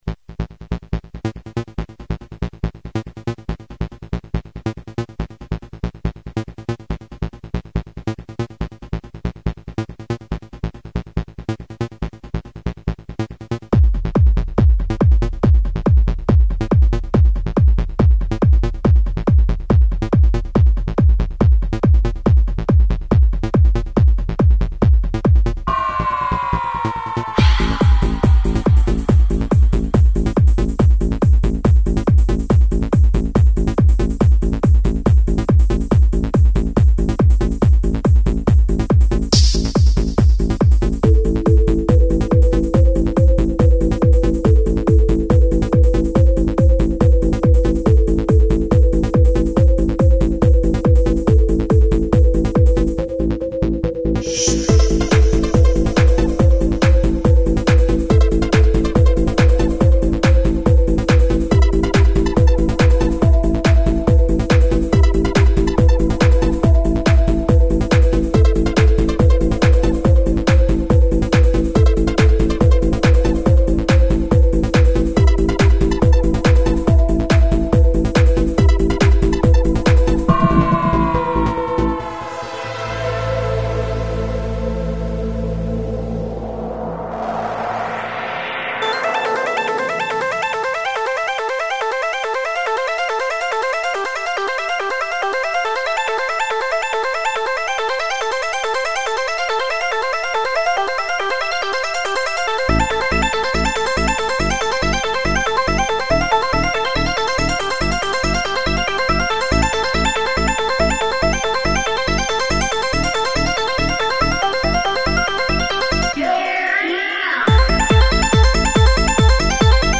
dance/electronic
Best played in sourround sound
Techno
Trance
Electro